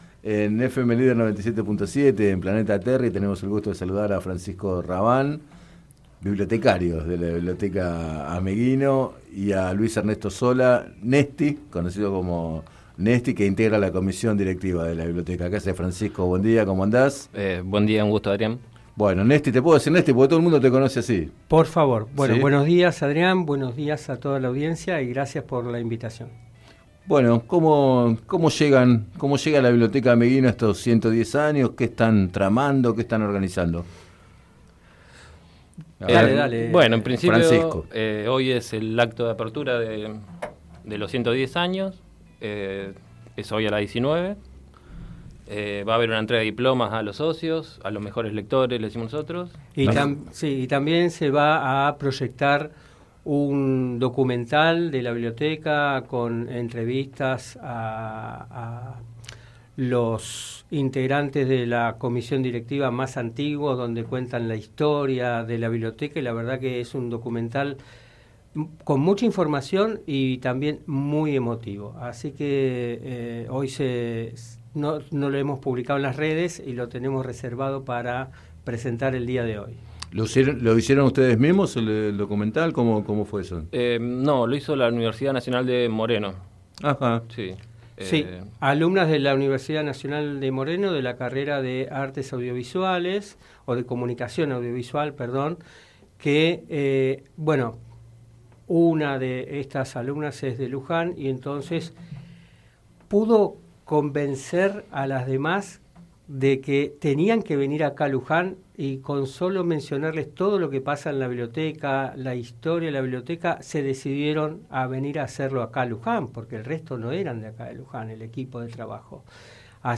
Entrevistados en el programa Planeta Terri de FM Líder 97.7